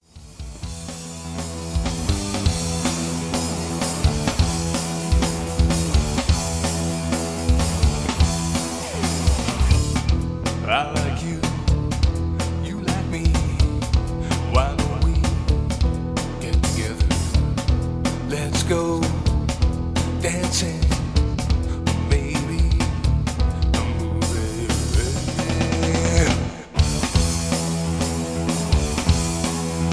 Comedy tune. Background music suitable for TV/Film use.